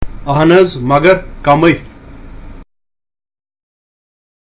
A CONVERSATION WITH A TEA SELLER